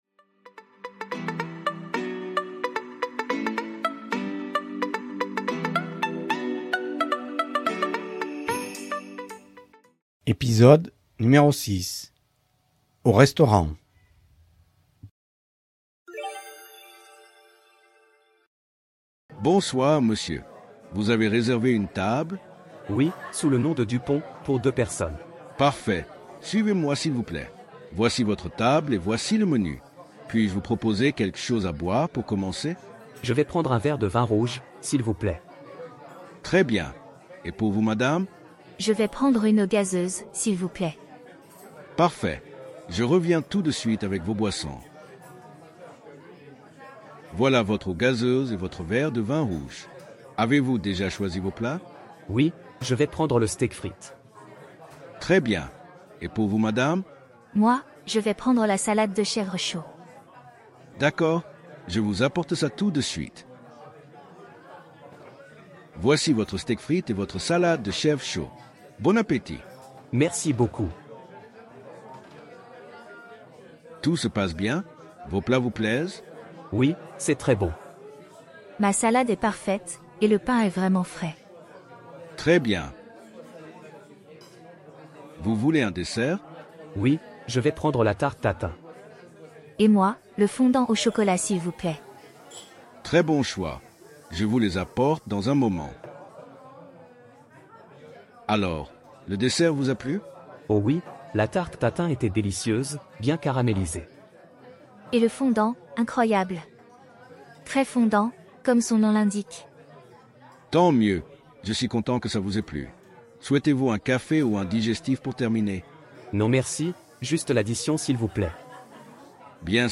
Voici un petit dialogue pour les débutants. Avec cet épisode, vous allez apprendre quelques expressions pour commander au restaurant.
006-Podcast-dialogues-Au-restaurant.mp3